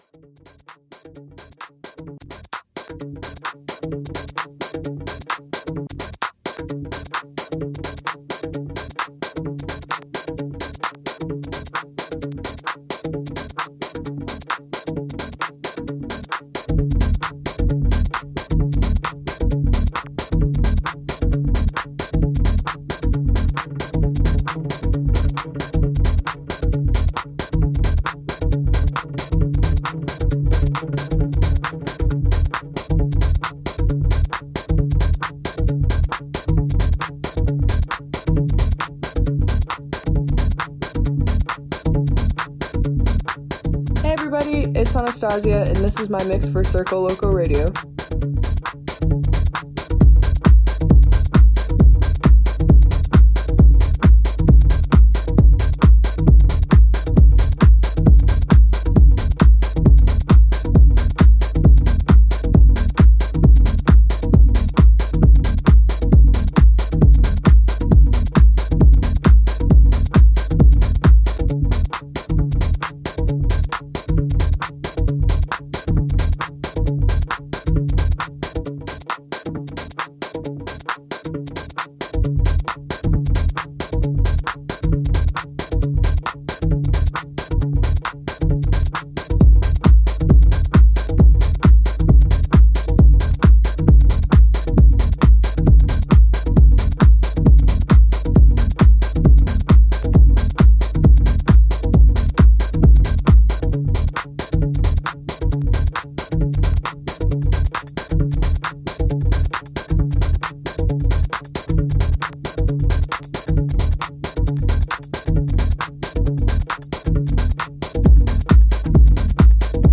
Bringing you new mixes from the best DJs in the world.